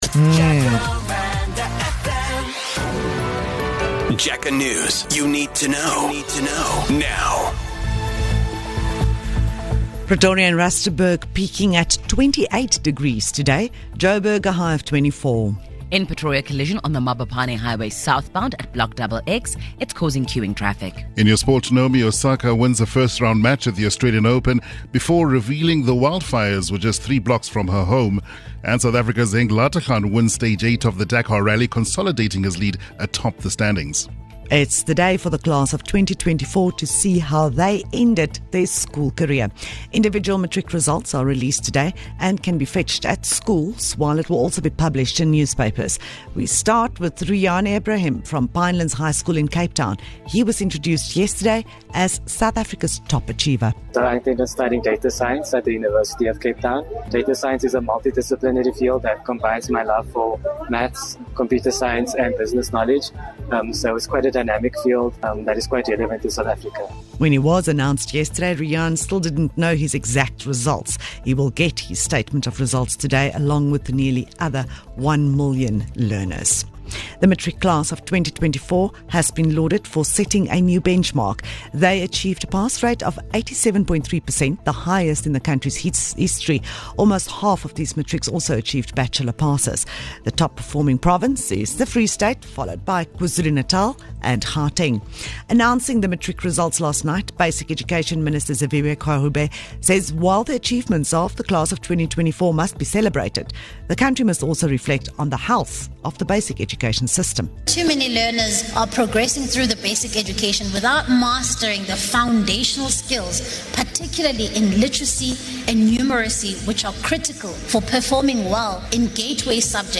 1 JacarandaFM News @ 07H01 4:30 Play Pause 14m ago 4:30 Play Pause Riproduci in seguito Riproduci in seguito Liste Like Like aggiunto 4:30 Here's your latest Jacaranda FM News bulletin.